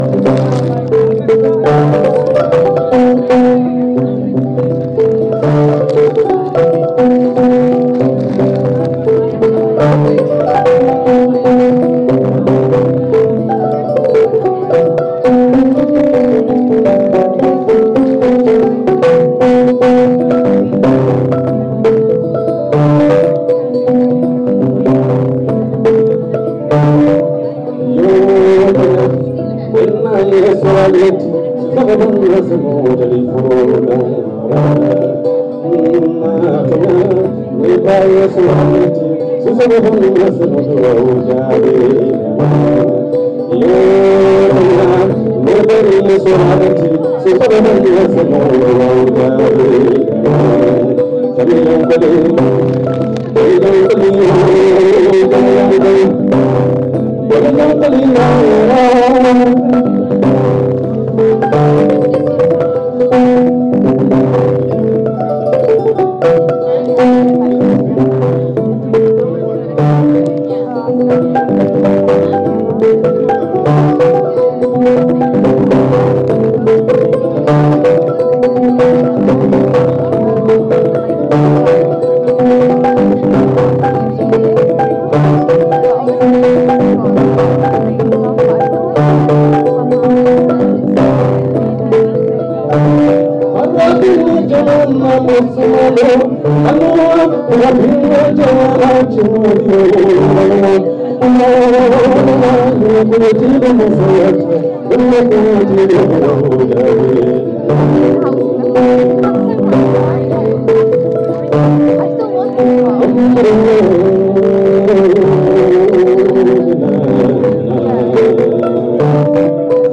Demo of vocals and West African instrument with amplification (kora) at soas
Sorry about the quality